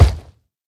sounds / mob / hoglin / step3.ogg
step3.ogg